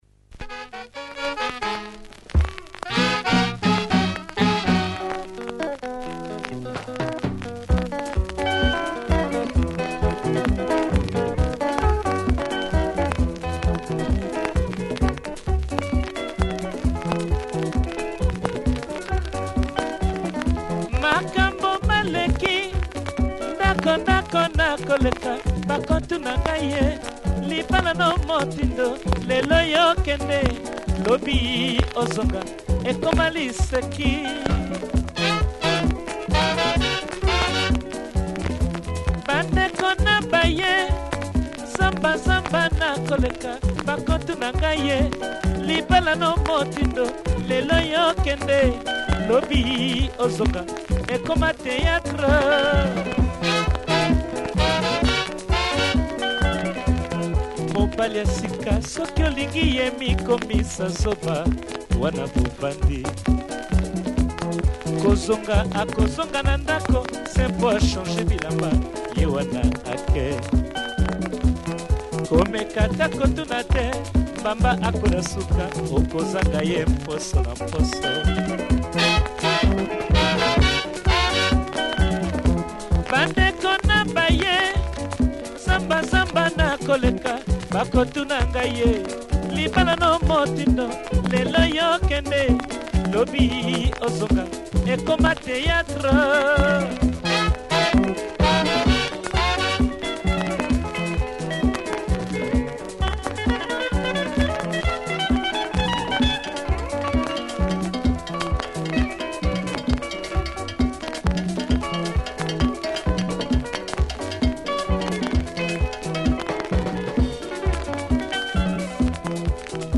Kickass late lingala, club potentia.
So it will sound better than the soundclip here.